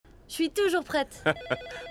IMDA - Voix enfant - Christmas Break in extrait 2